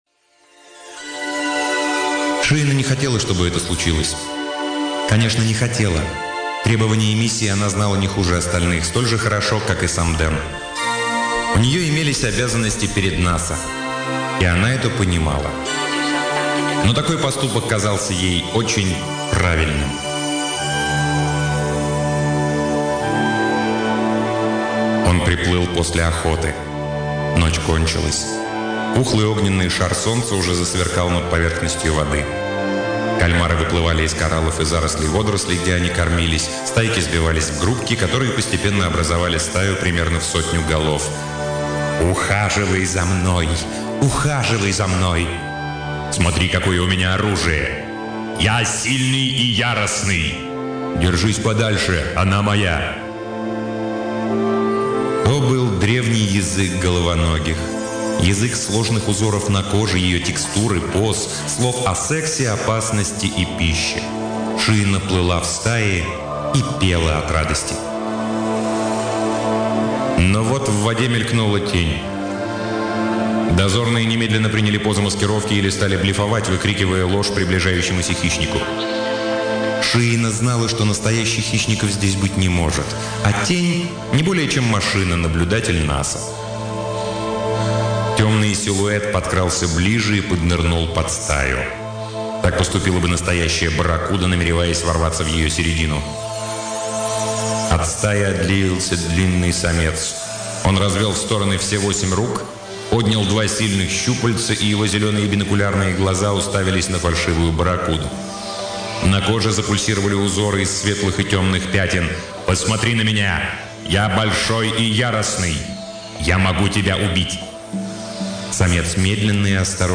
Аудиокниги передачи «Модель для сборки» онлайн